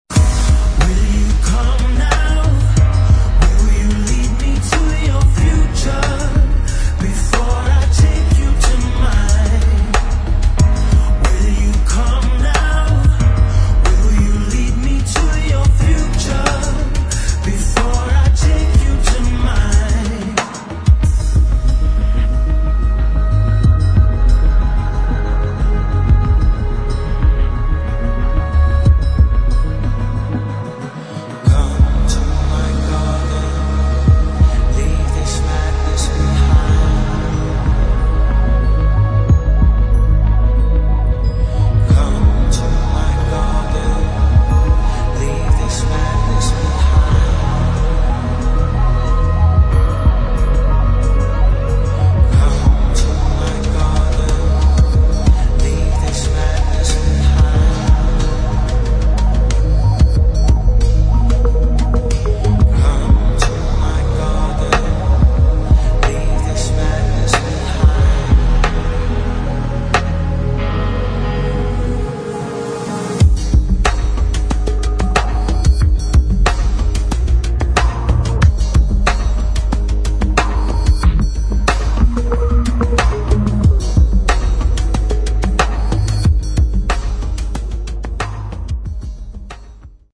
[ HOUSE | DUB | ELECTRONIC ]